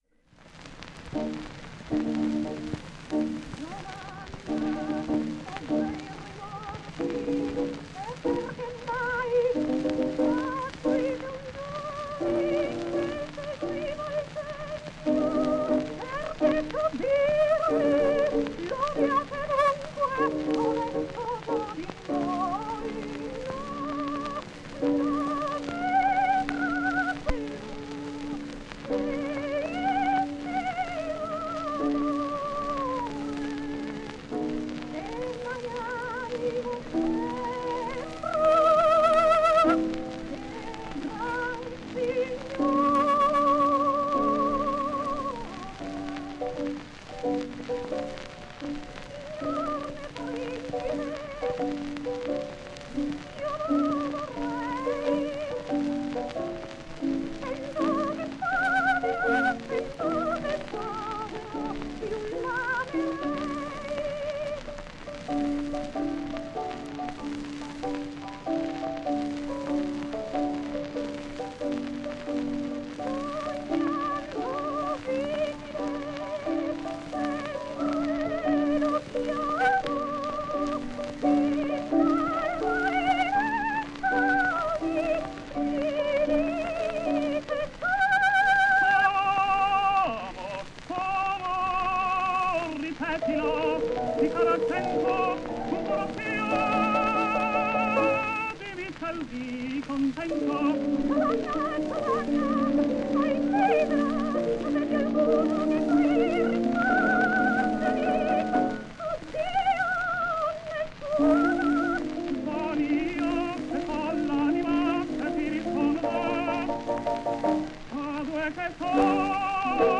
tenore